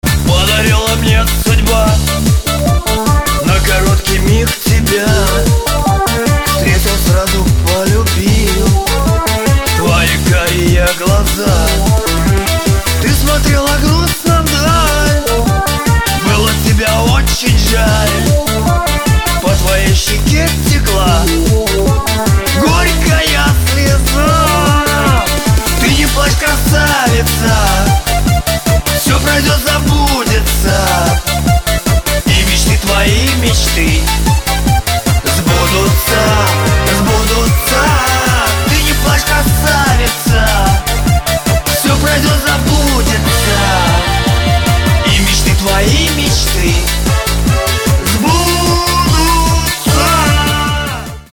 • Качество: 256, Stereo
мужской вокал
громкие
русский шансон